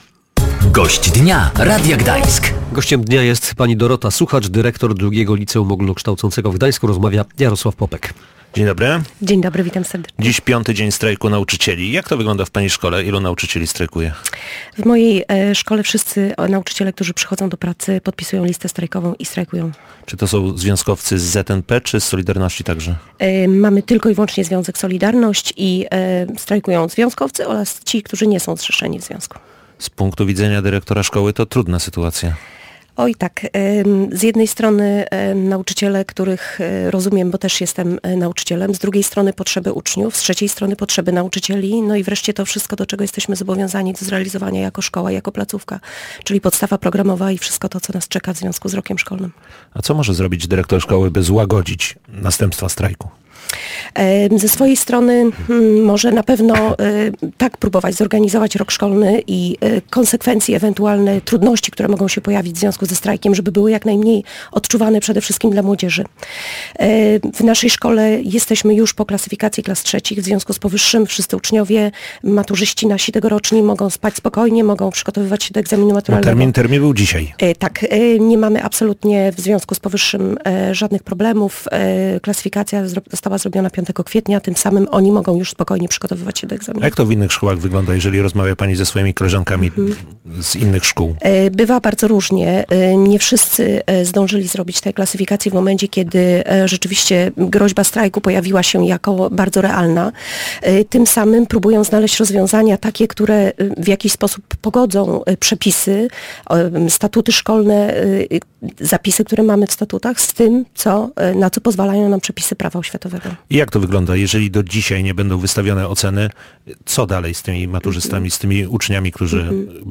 /audio/dok3/gd120419.mp3 Tagi: audycje Gość Dnia Radia Gdańsk